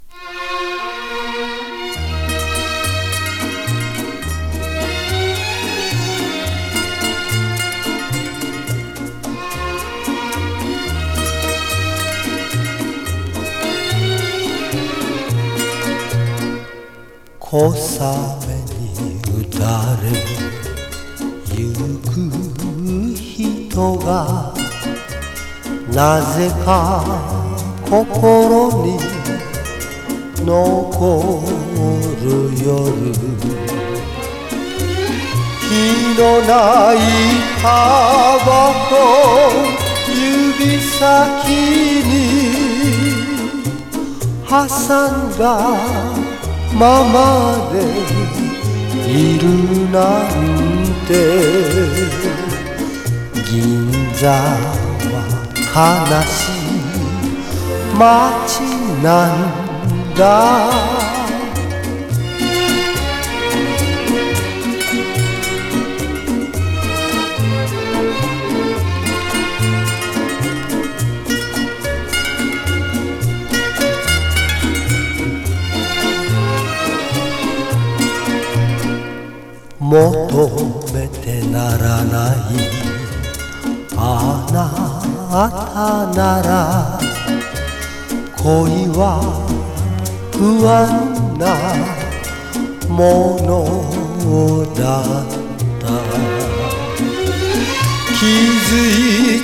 マイナームード歌謡もの。